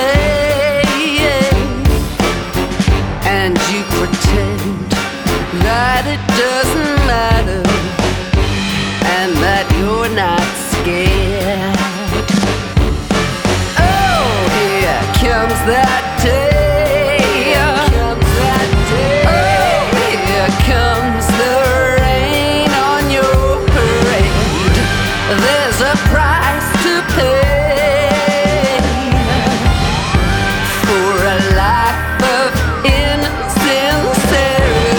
Жанр: Рок / Альтернатива
Alternative, Rock, Adult Alternative, Goth Rock, Indie Rock